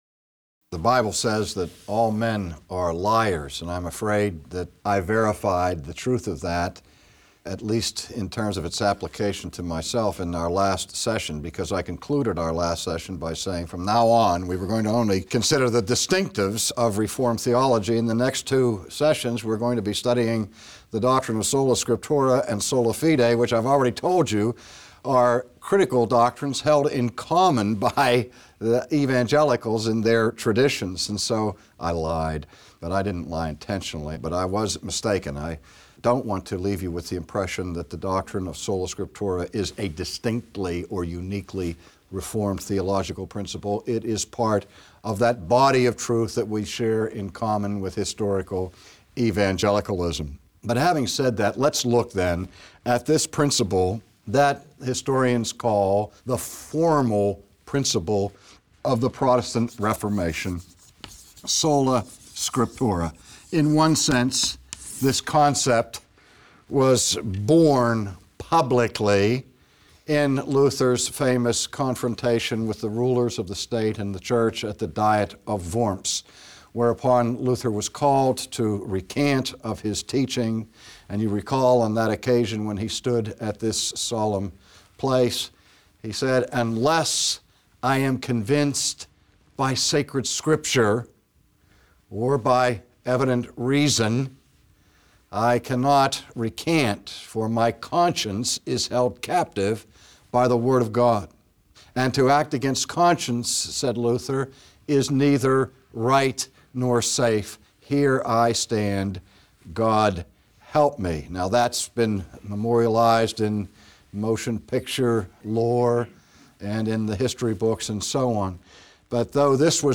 Lecture #3 : Scripture Alone Christians ought to submit to the authority given them by Christ. But what happens when those in authority teach things contrary to the Word of God?
Dr. Sproul teaches us about this in this message entitled “Scripture Alone.”